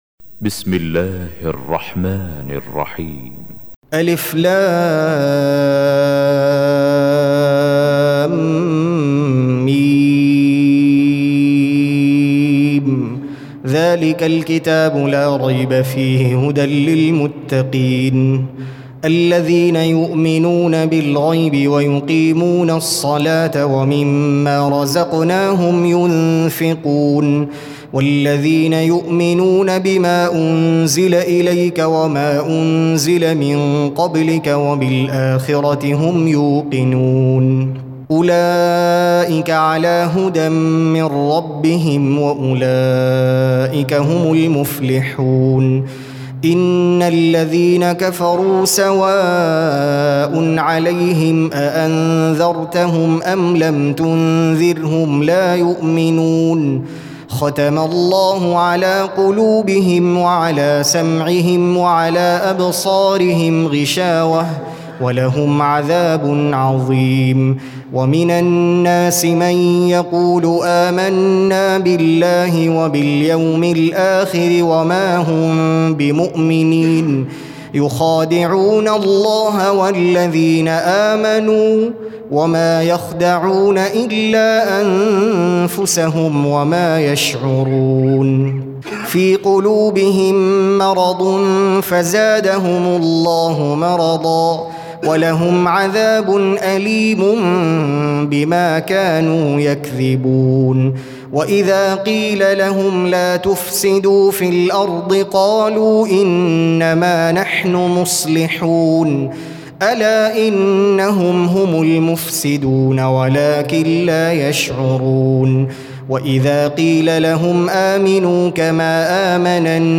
2. Surah Al-Baqarah سورة البقرة Audio Quran Tarteel Recitation
حفص عن عاصم Hafs for Assem